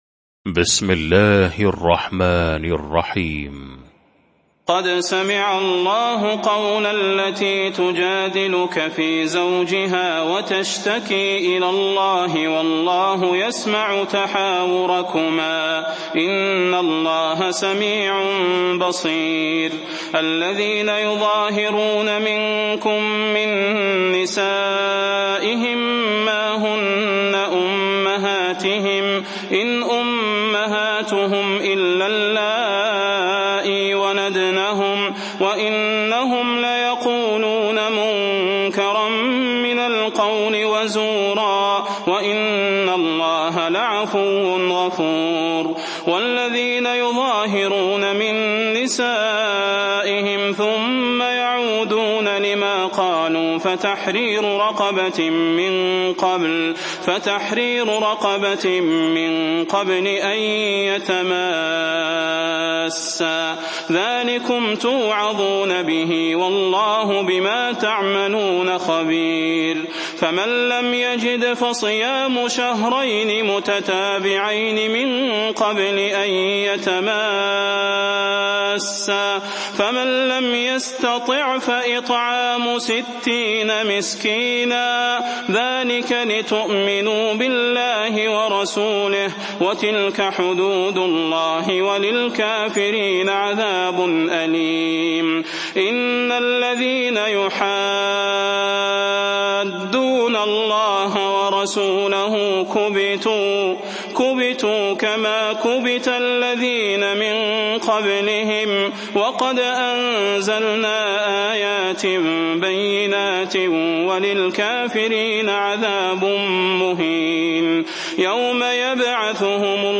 المكان: المسجد النبوي الشيخ: فضيلة الشيخ د. صلاح بن محمد البدير فضيلة الشيخ د. صلاح بن محمد البدير المجادلة